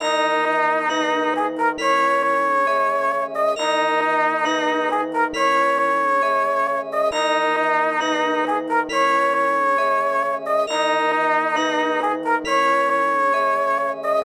135 bpm